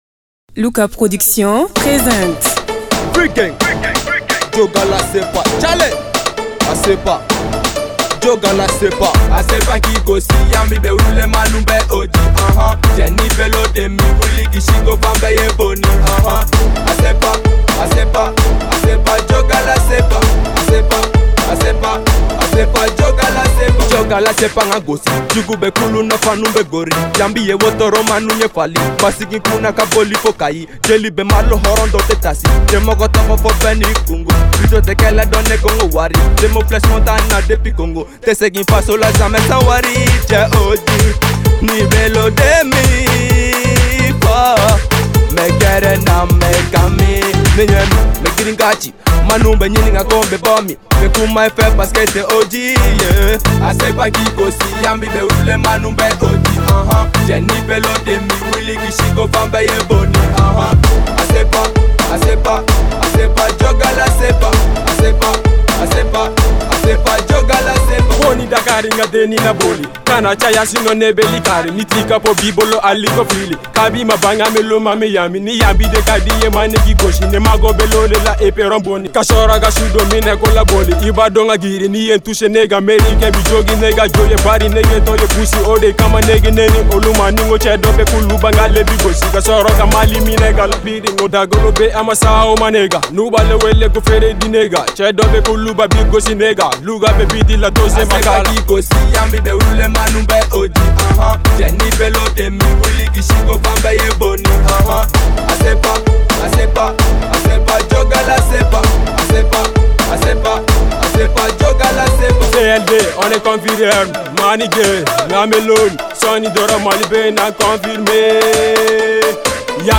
rap Malien